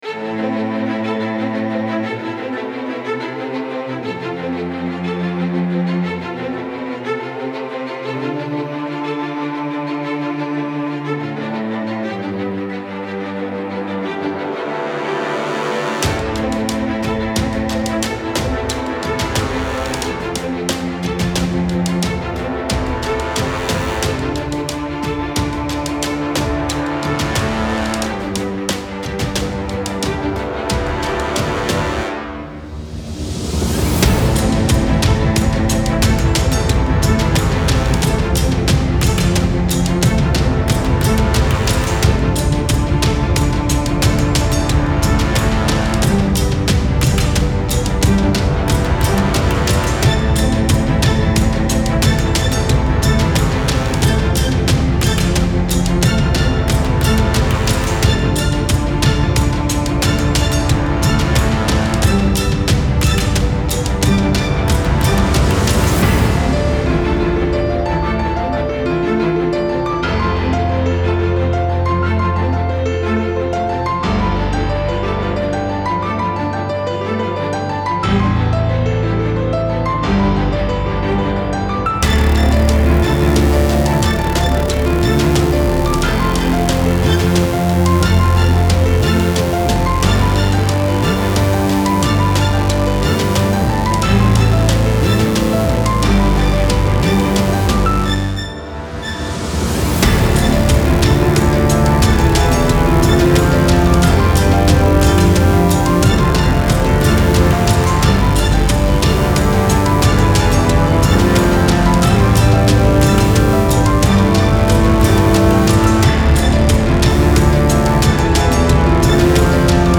Style Style Orchestral, Soundtrack
Mood Mood Epic, Uplifting
Featured Featured Bass, Brass, Cello +5 more
BPM BPM 90